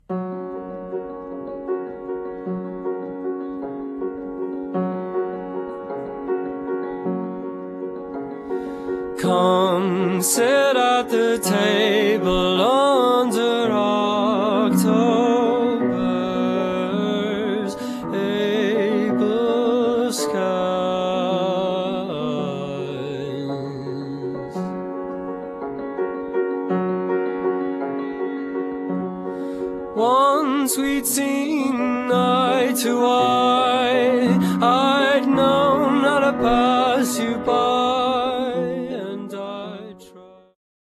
euphonium
upright bass
baritone saxophone